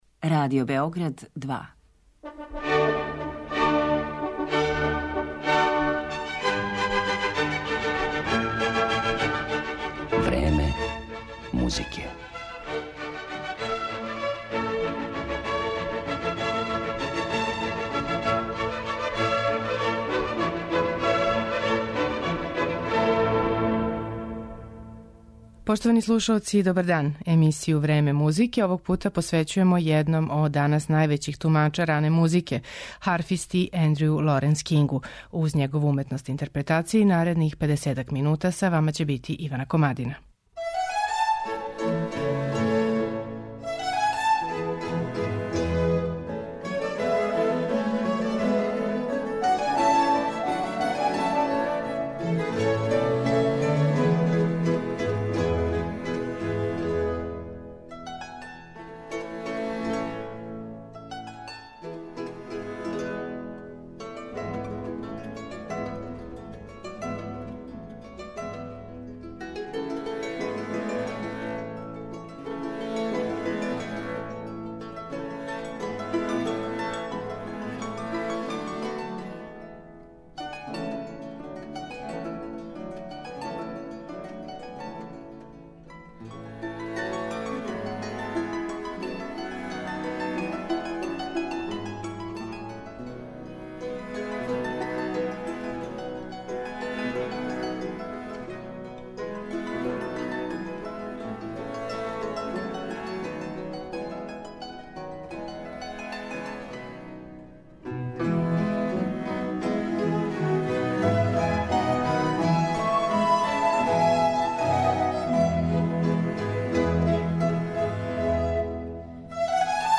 Емисију смо посветили славном британском харфисти.